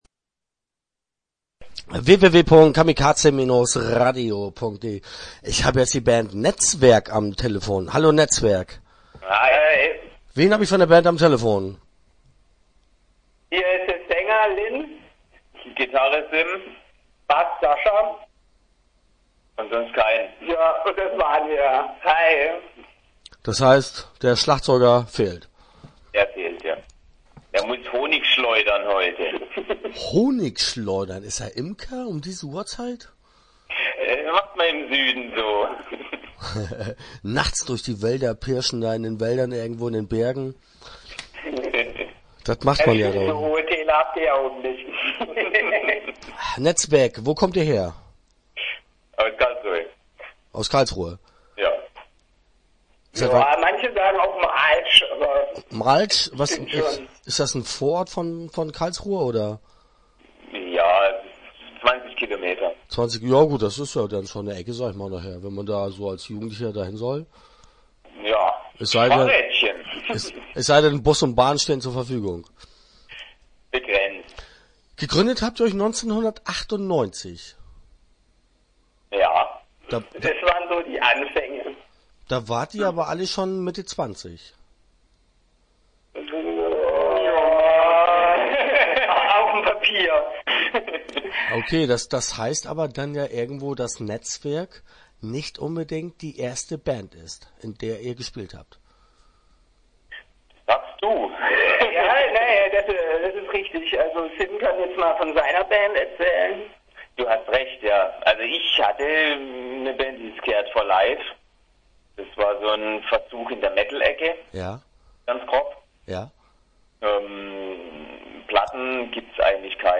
Interview Teil 1 (10:43)